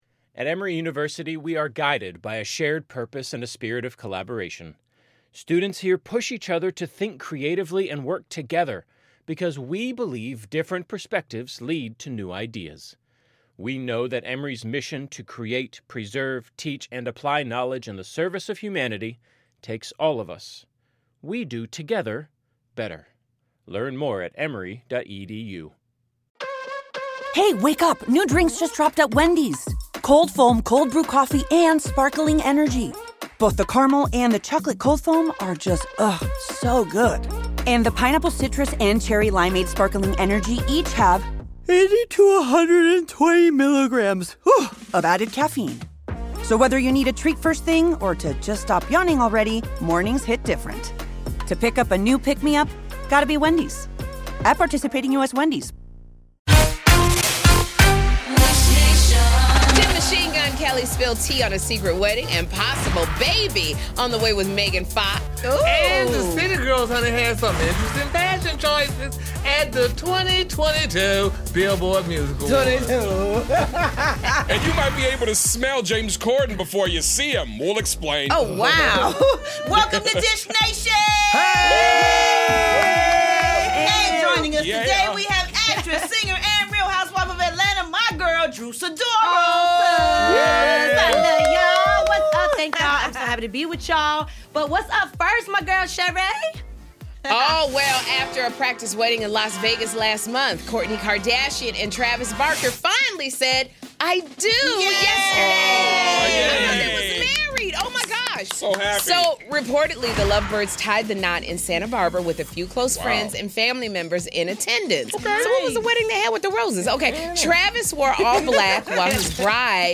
'Real Housewives of Atlanta' star Drew Sidora cohosts with us and she's spillin' some peachy tea! Plus, Kourtney Kardashian and Travis Barker get hitched, but is it real this time?